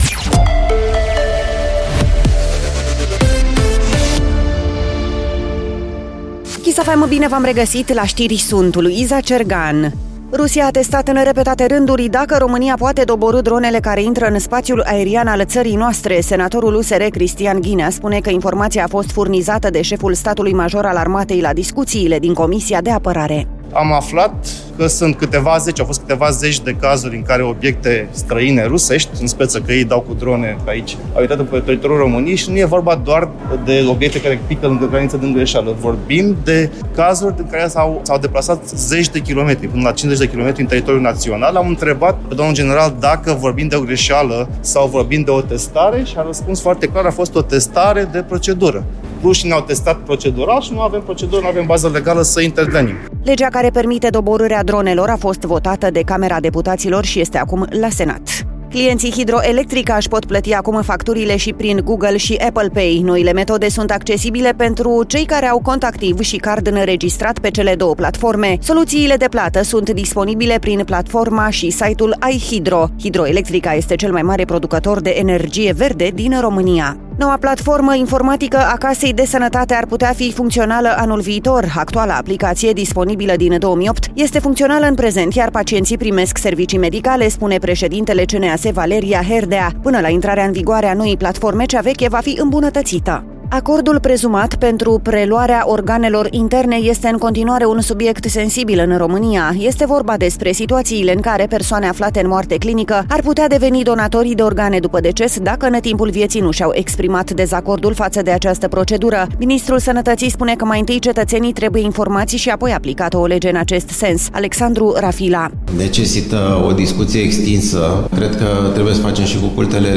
Știrile zilei de la Kiss FM